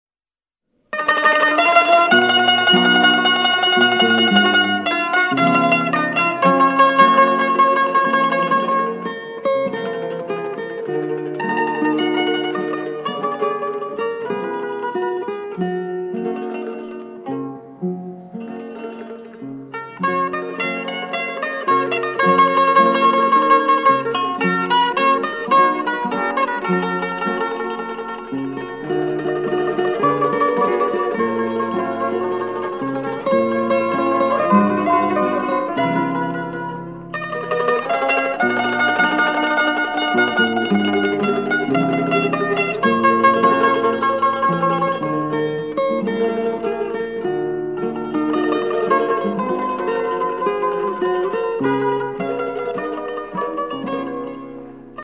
• Теги: минусовка
Минусовка.